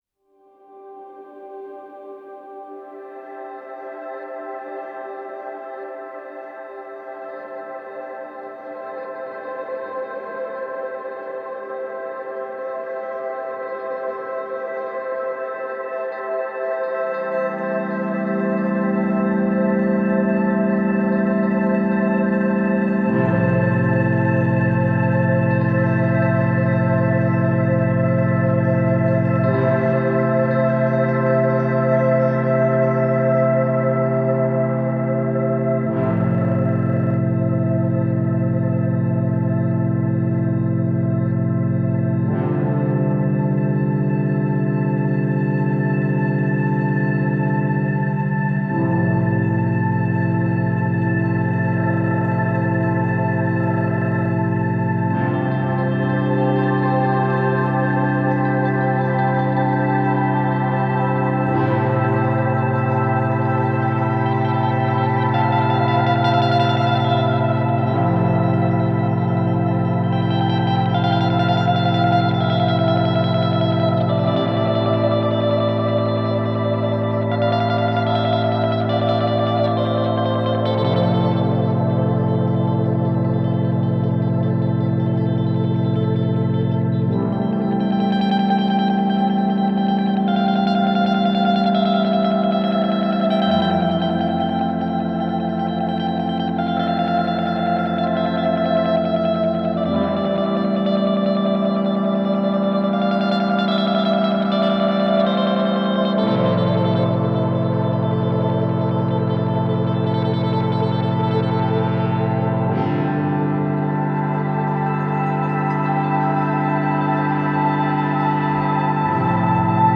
Shimmering synths, textured guitar and deep bass.